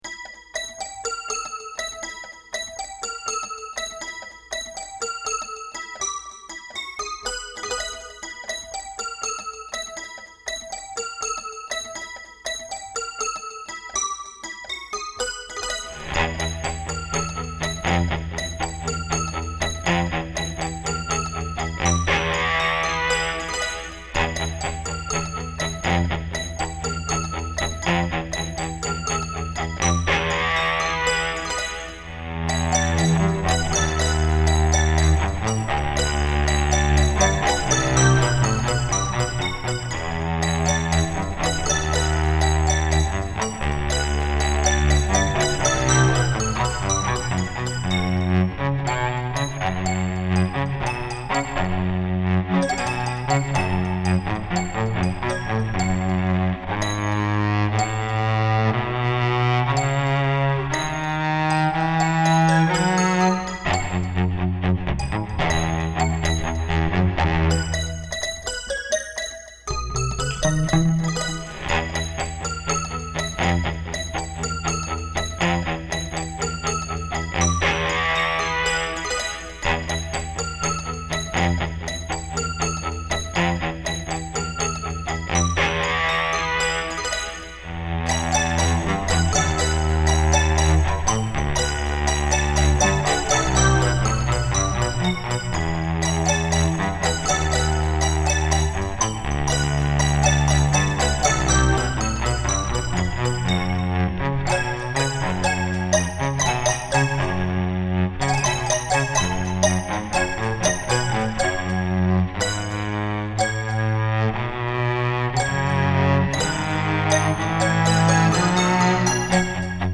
on toy piano and cello